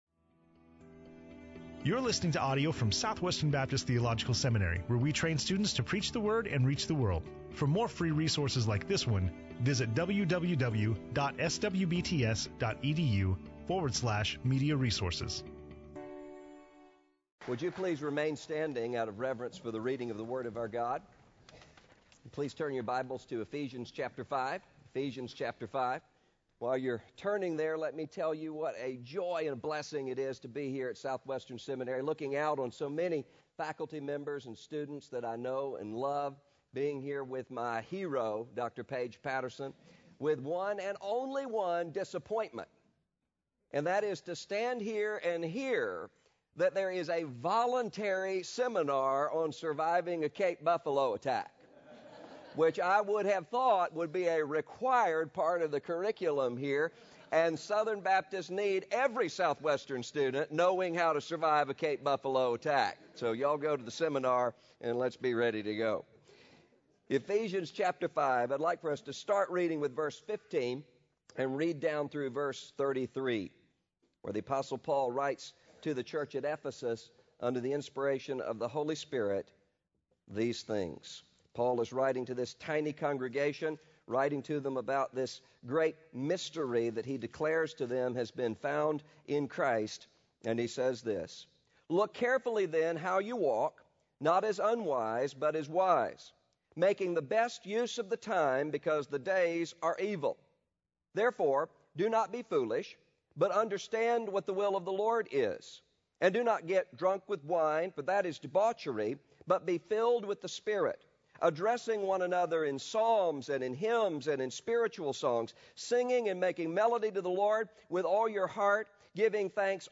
Dr. Russell Moore speaking on Ephesians 5:15-33 in SWBTS Chapel on Tuesday September 13, 2011